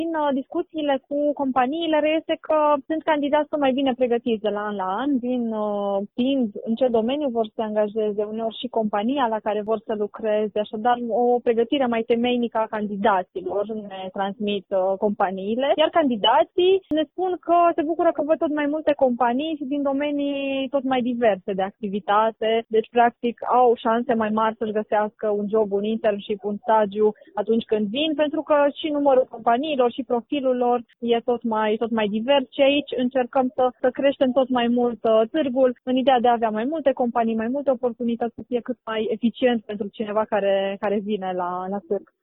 Reprezentantul organizatorilor